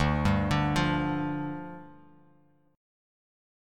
Dmbb5 Chord
Listen to Dmbb5 strummed